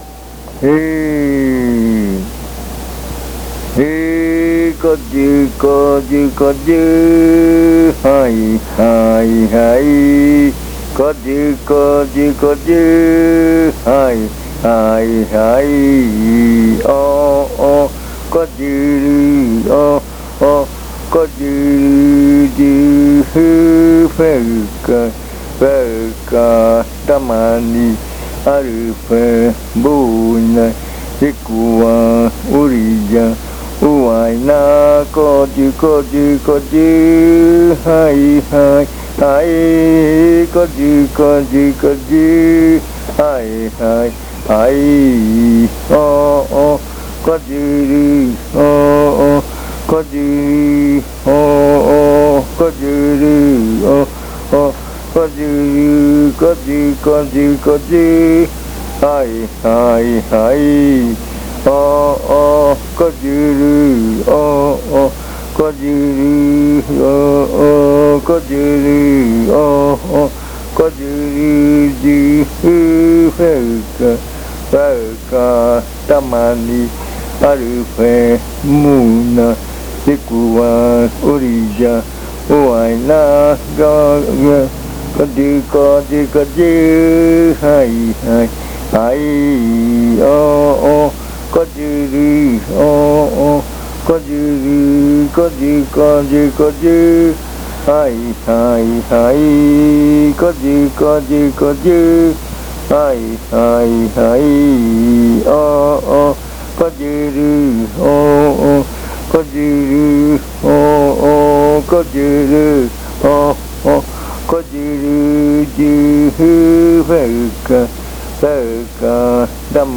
Este canto hace parte de la colección de cantos del ritual Yuakɨ Murui-Muina (ritual de frutas) del pueblo Murui
Cantos de yuakɨ